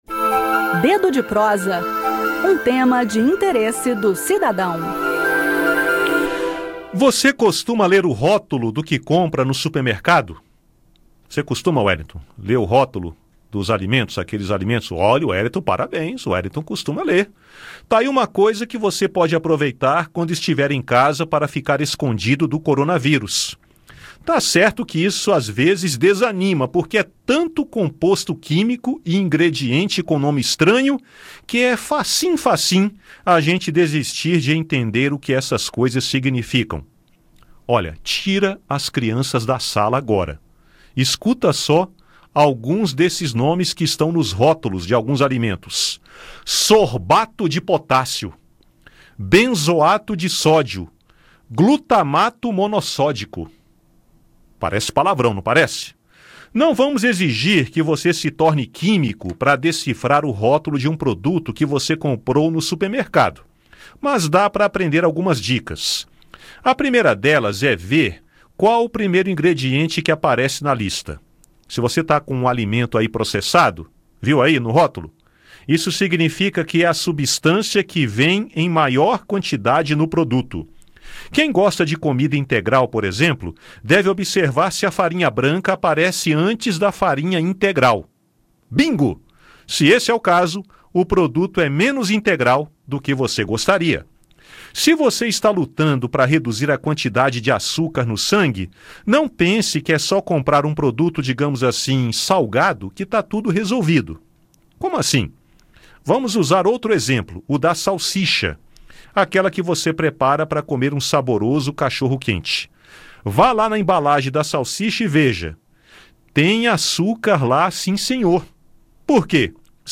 Ouça o bate-papo